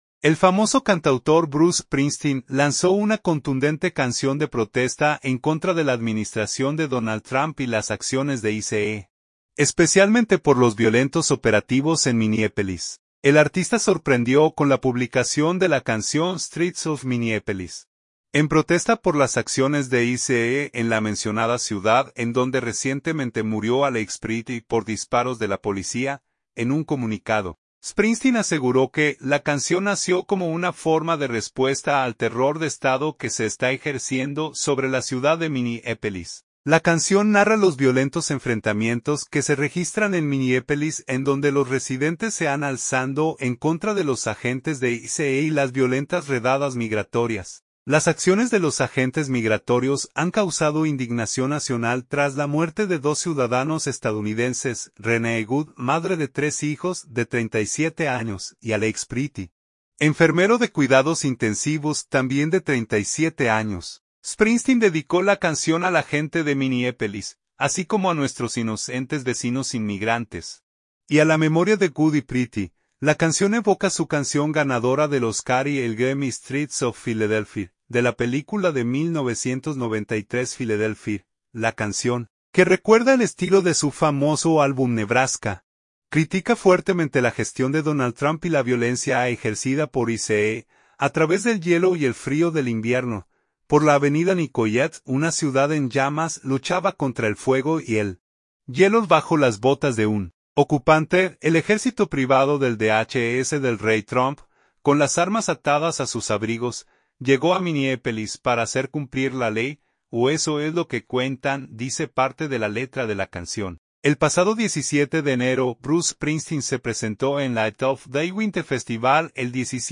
contundente canción de protesta